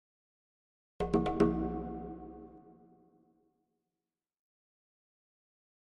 Jungle Drums Triple Hits Version 1